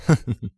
XS嘲弄2.wav
XS嘲弄2.wav 0:00.00 0:00.58 XS嘲弄2.wav WAV · 50 KB · 單聲道 (1ch) 下载文件 本站所有音效均采用 CC0 授权 ，可免费用于商业与个人项目，无需署名。
人声采集素材